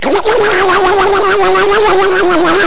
SAWRUN.mp3